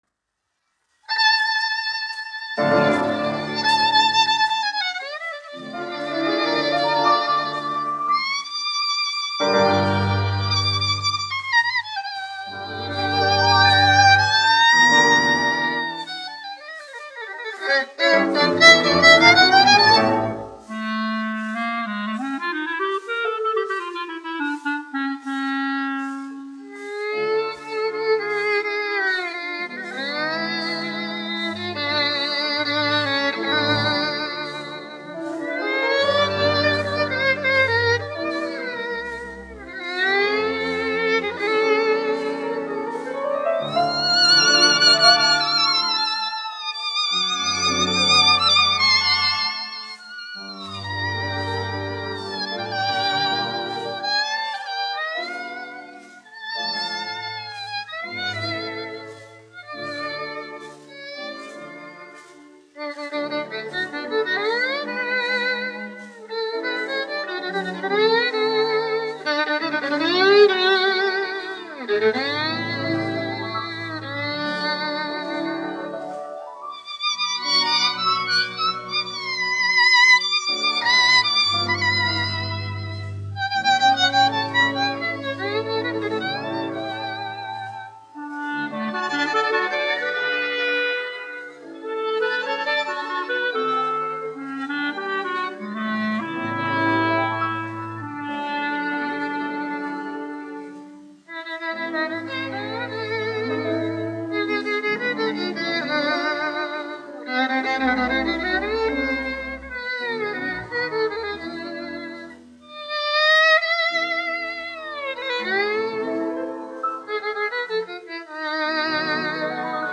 1 skpl. : analogs, 78 apgr/min, mono ; 25 cm
Vijole ar orķestri
Tautas mūzika--Ungārija
Latvijas vēsturiskie šellaka skaņuplašu ieraksti (Kolekcija)